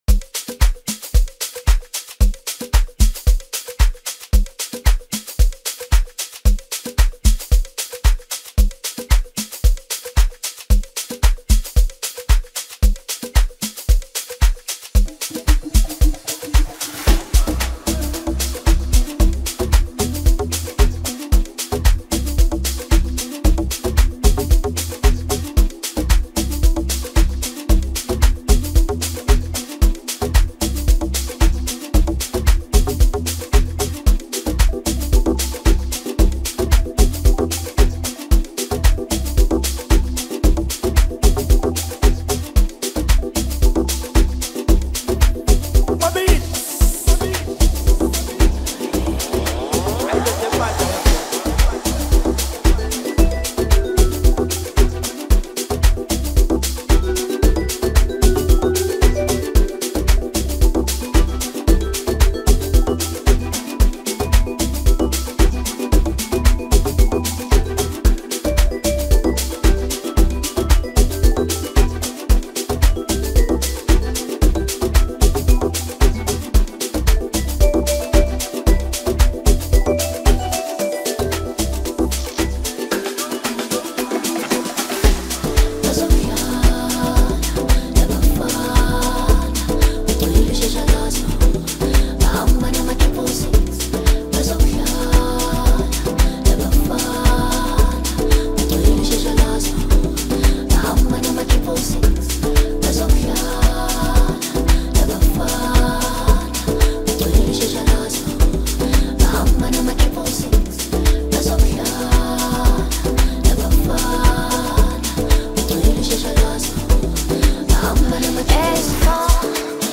Listeners are treated to a refreshing vibe